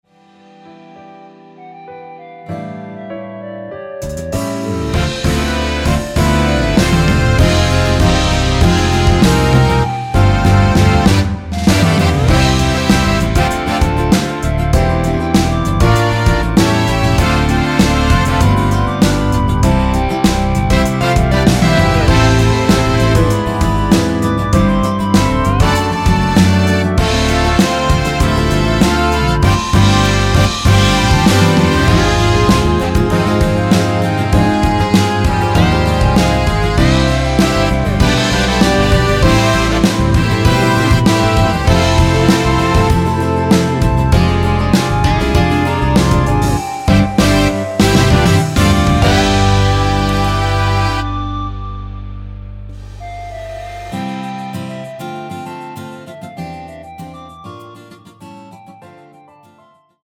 원키 멜로디 포함된(1절+후렴)으로 진행되는 MR입니다.
앞부분30초, 뒷부분30초씩 편집해서 올려 드리고 있습니다.
중간에 음이 끈어지고 다시 나오는 이유는